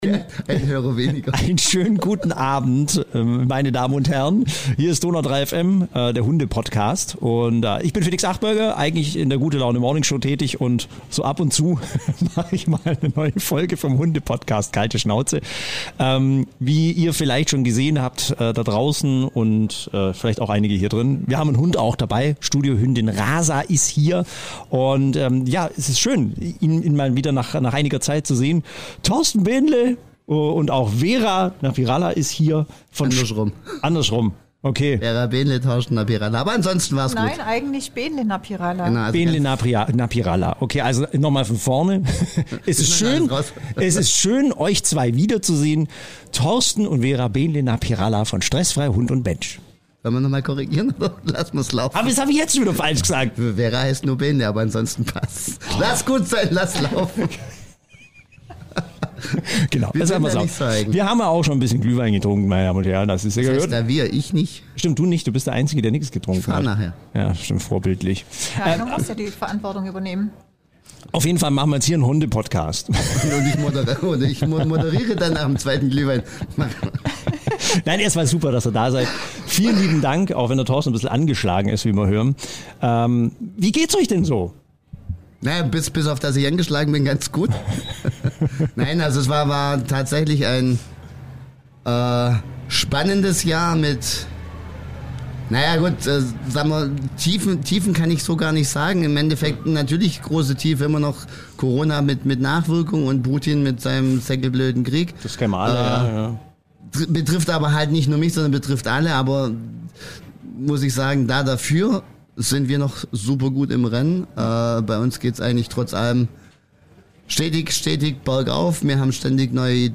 zu Gast im Studio ~ Ulmer Weihnachtsmarkt-Podcast